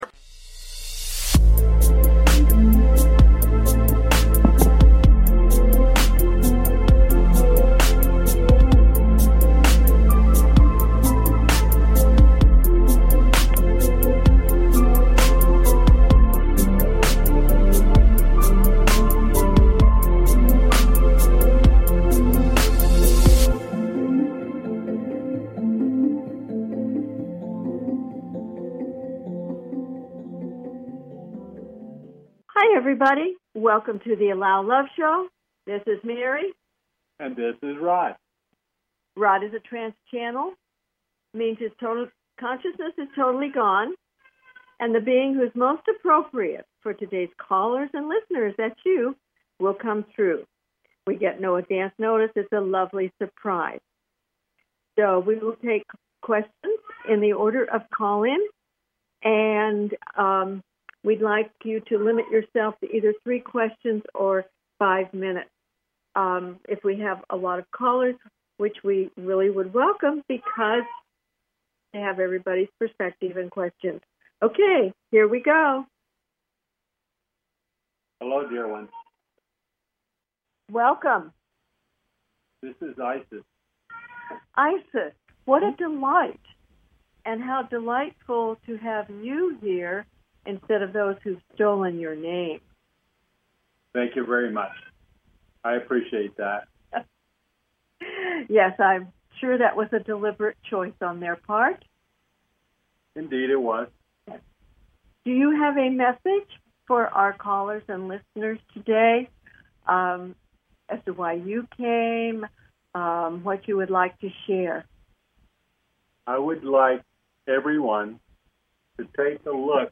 Talk Show Episode, Audio Podcast, The Allow Love Show and with ISIS on , show guests , about ISIS, categorized as Paranormal,Ghosts,Philosophy,Spiritual,Access Consciousness,Medium & Channeling
Their purpose is to provide answers to callers’ questions and to facilitate advice as callers request.